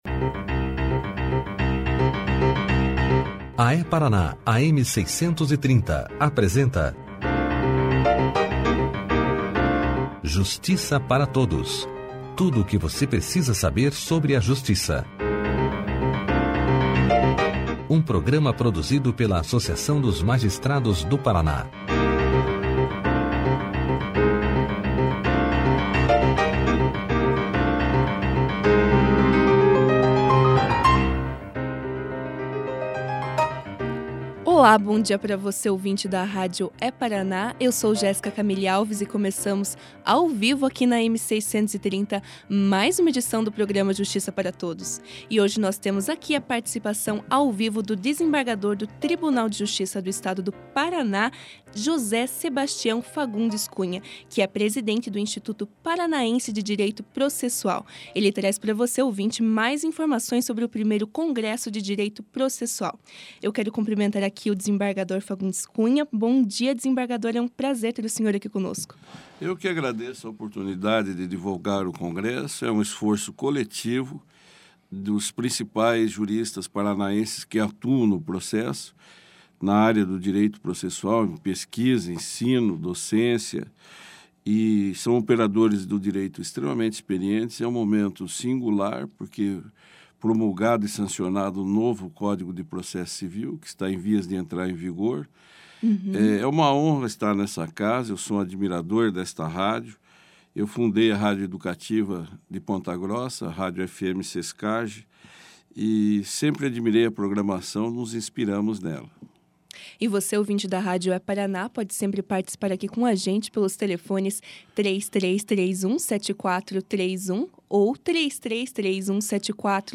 No programa Justiça Para Todos dessa quarta-feira (30), o desembargador e presidente do Instituto Paranaense de Direito Processual, José Sebastião Fagundes Cunha levou aos ouvintes da rádio É-Paraná, AM630, maiores informações sobre o I Congresso de Direito Processual, que acontecerá em Curitiba, nos dias 28 a 30 de Outubro de 2015.
Clique aqui e ouça a entrevista do desembargador José Sebastião Fagundes Cunha sobre o I Congresso de Direito Processual na íntegra.